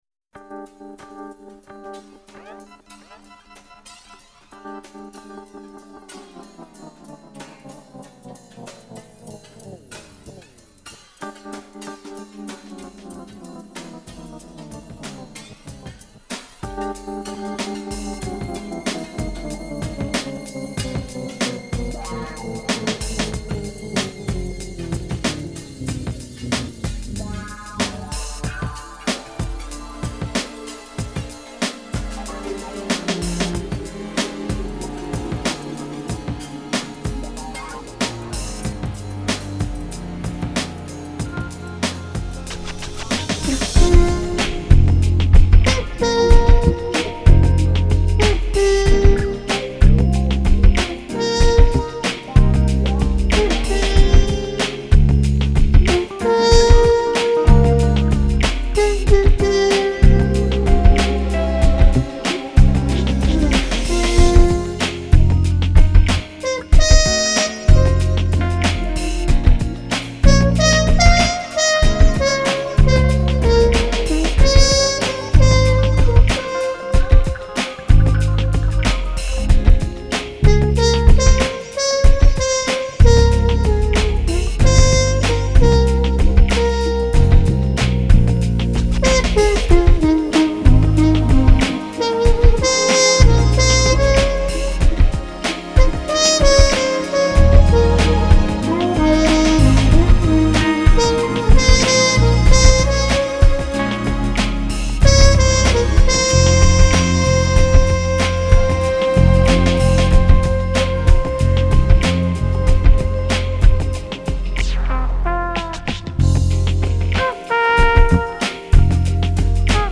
известный трубач из США